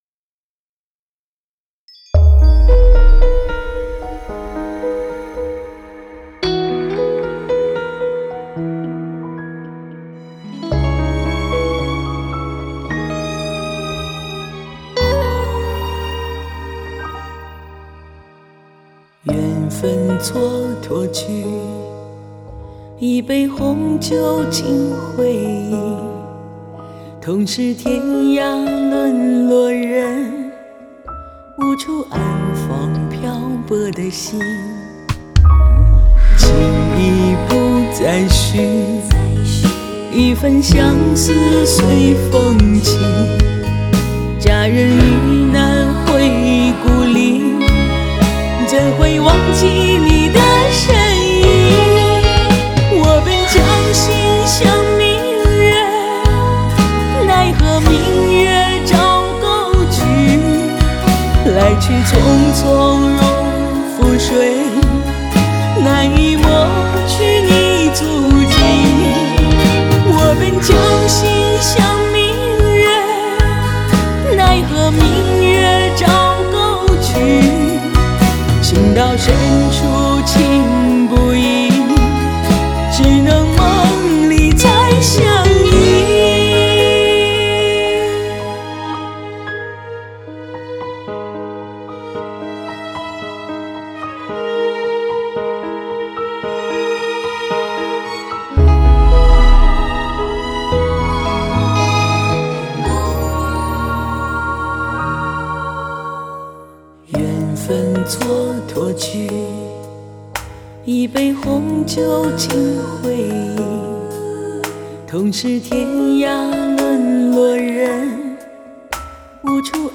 Ps：在线试听为压缩音质节选，体验无损音质请下载完整版 https